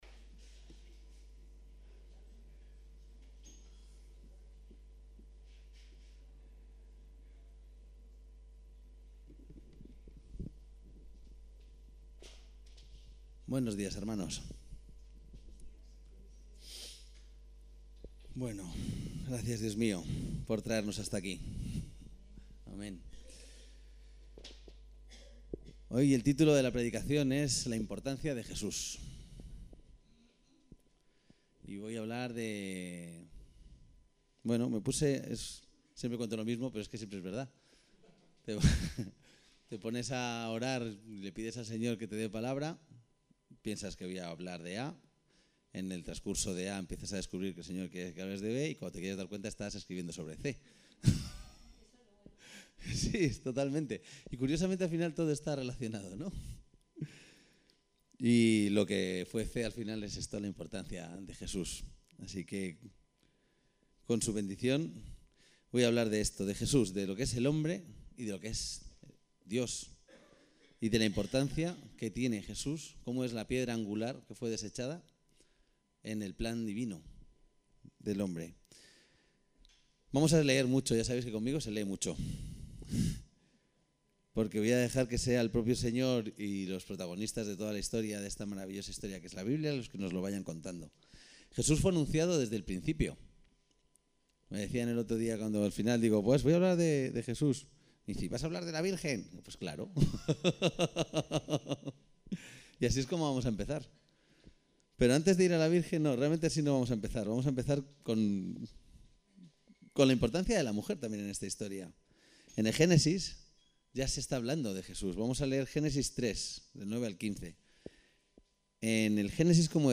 Predicación para leer: La importancia de Jesús